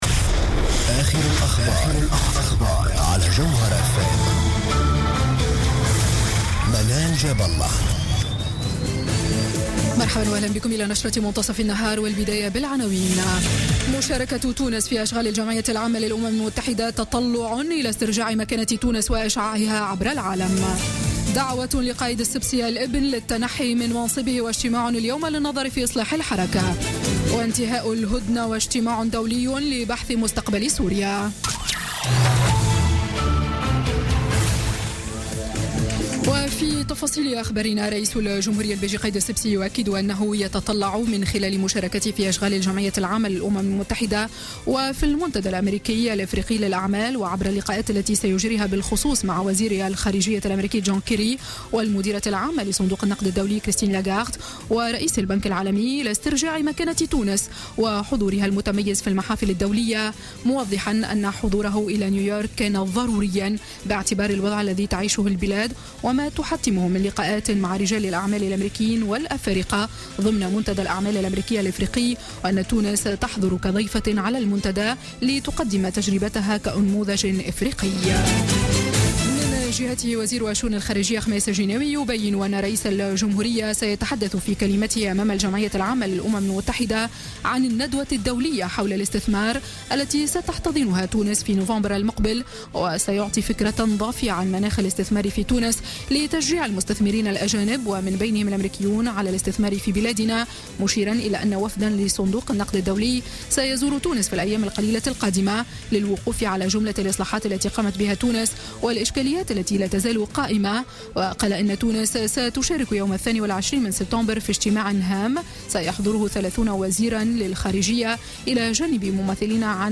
Journal Info 12h00 du lundi 19 septembre 2016